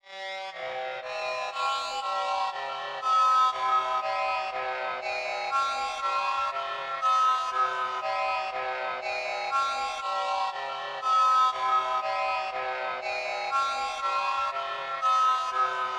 EN - Bliss (120 BPM).wav